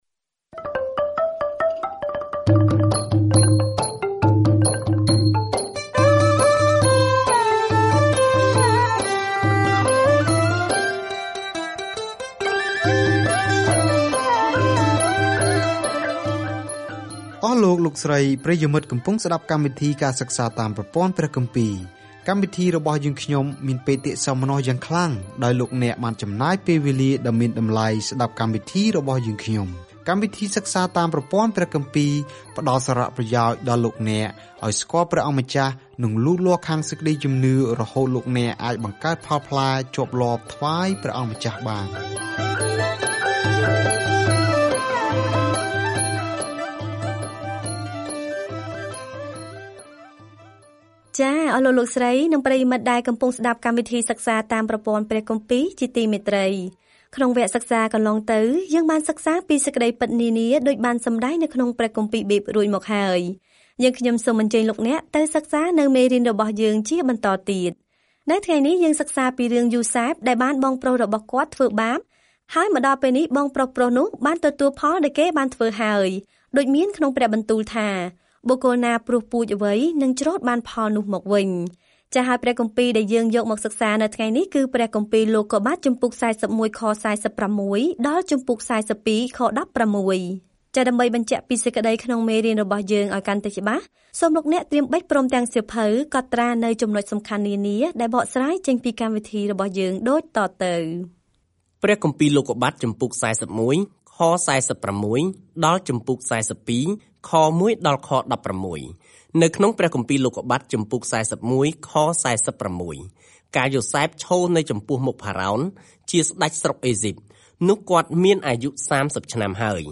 There is an audio attachment for this devotional.
ការធ្វើដំណើរប្រចាំថ្ងៃតាមរយៈលោកុប្បត្តិ នៅពេលអ្នកស្តាប់ការសិក្សាជាសំឡេង ហើយអានខគម្ពីរដែលបានជ្រើសរើសពីព្រះបន្ទូលរបស់ព្រះនៅក្នុងសៀវភៅលោកុប្បត្តិ។